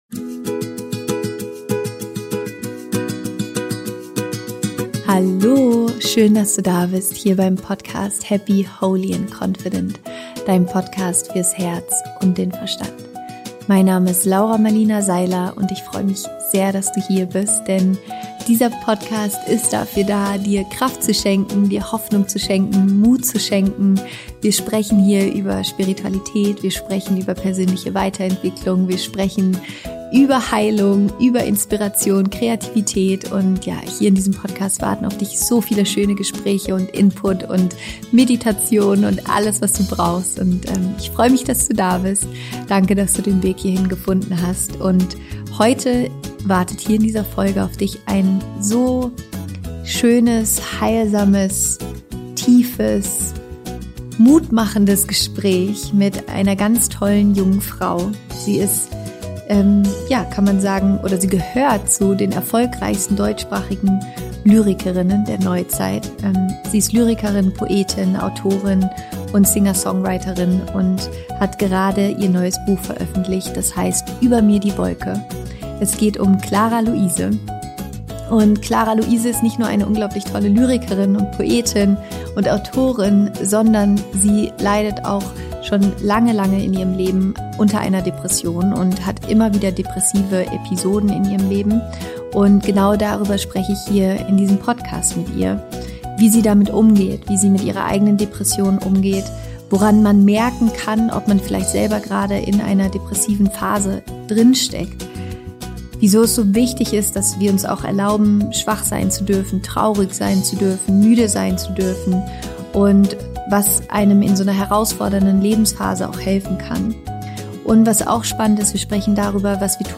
Wie du depressive Phasen gut überstehst - Interview Special mit Clara Louise